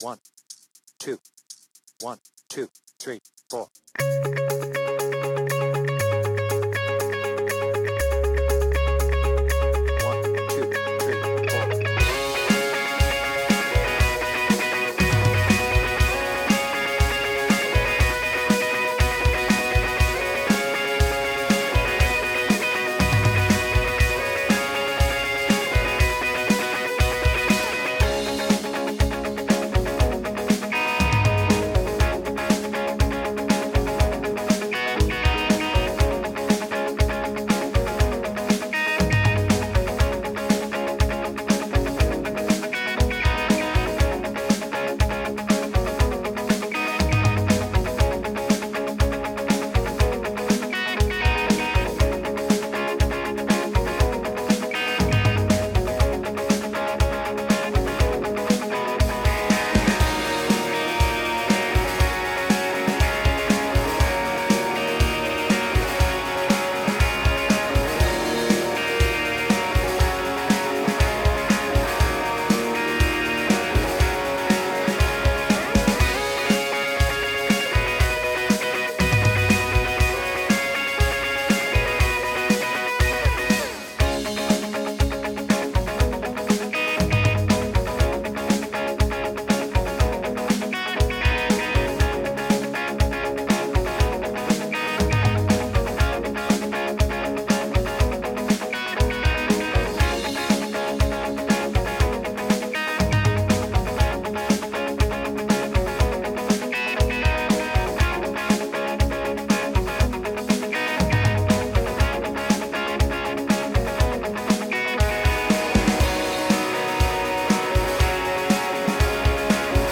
BPM : 120
Tuning : Eb
Without vocals